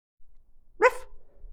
Woof